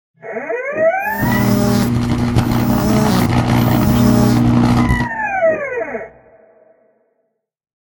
shock-short.ogg